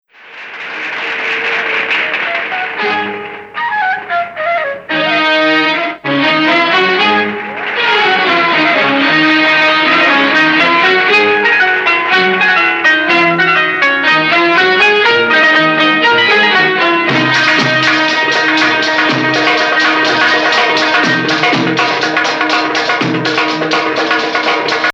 Bayati 1
goes up to 5 in final phrase